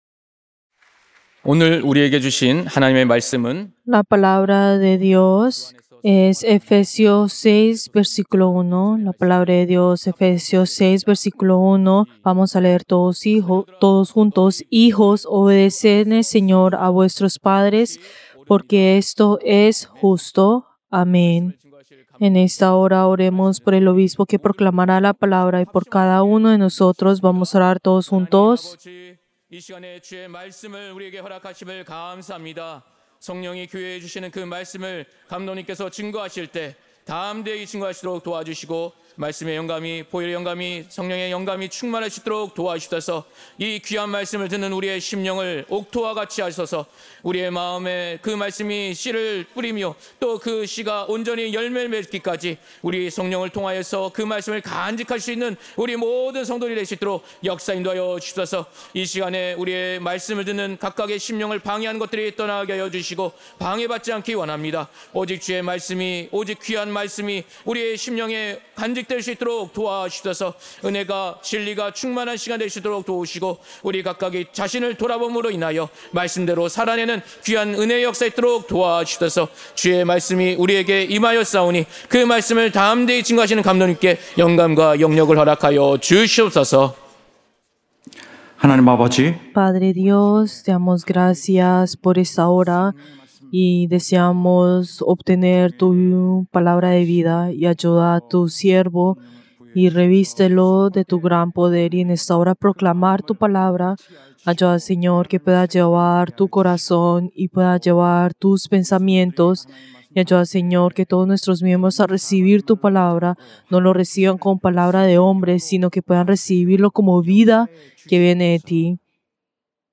Servicio del Día del Señor del 6 de abril del 2025